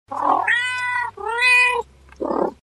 Звуки мяуканья кошки
10. Игриво: